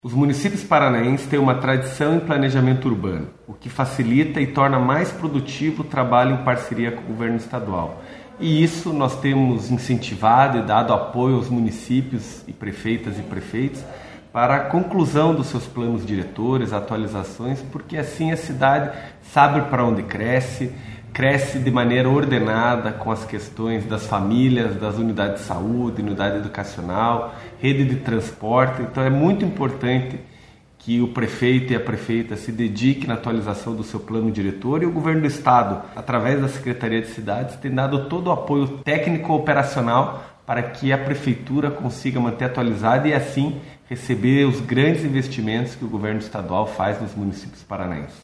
Sonora do secretário das Cidades, Eduardo Pimentel, sobre a liderança do Paraná na elaboração de planos diretores pelos municípios